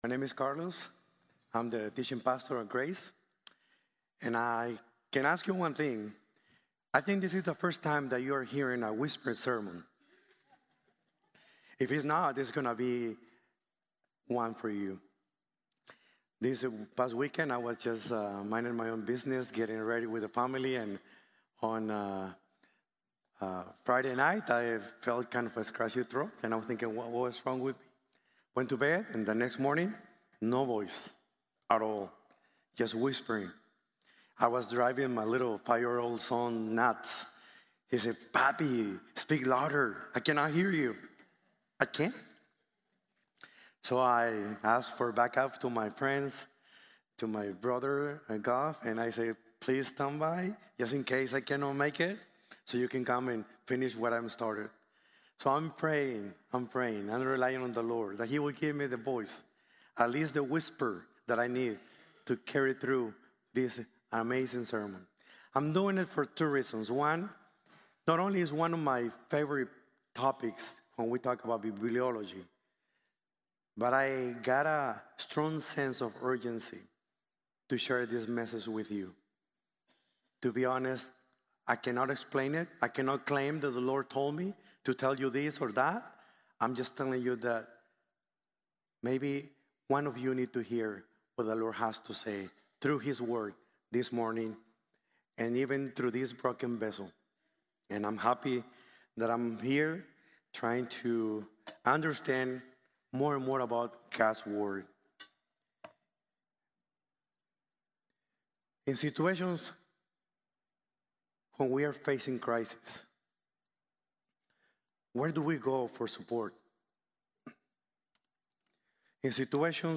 Bibliology: The Study of God’s Word | Sermon | Grace Bible Church